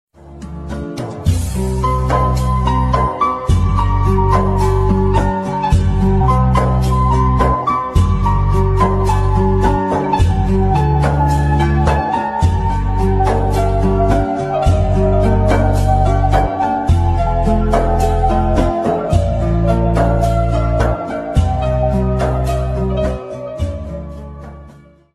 Nice Music (Instrumental)